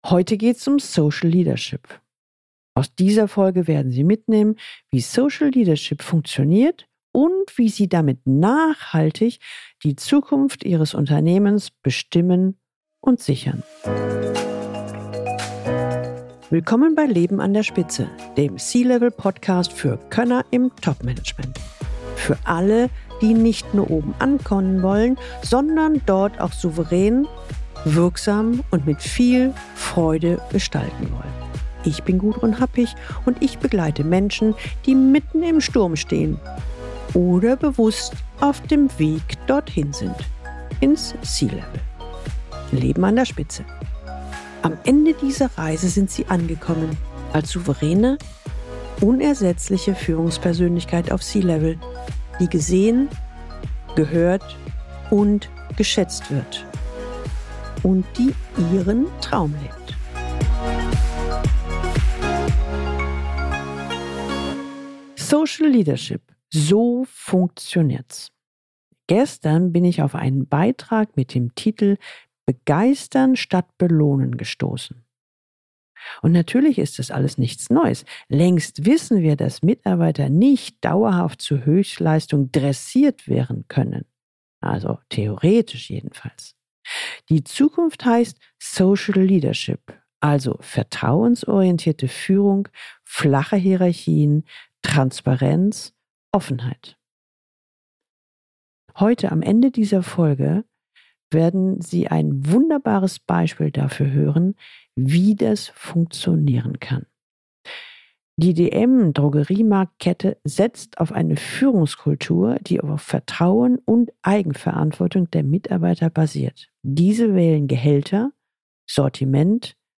Was ist Social Leadership – Interview mit Götz Werner – Interviews | Raus aus dem Hamsterrad #306